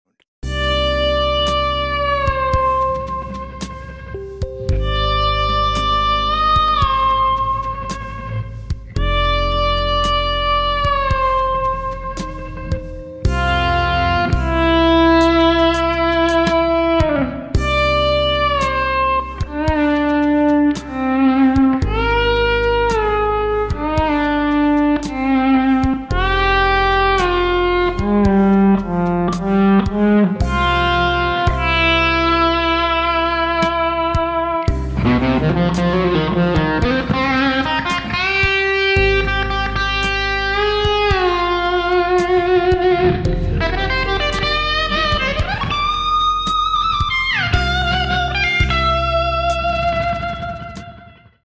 EHX Attack Decay Tape Reverse Sound Effects Free Download